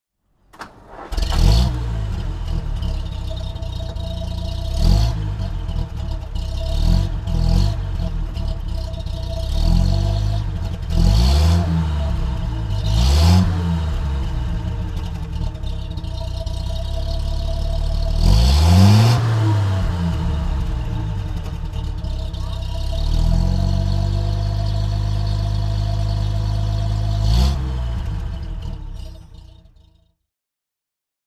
Motorsounds und Tonaufnahmen zu Bentley Fahrzeugen (zufällige Auswahl)
Bentley 3,5 Litre Park Ward Sports Saloon (1935) - Starten und Leerlauf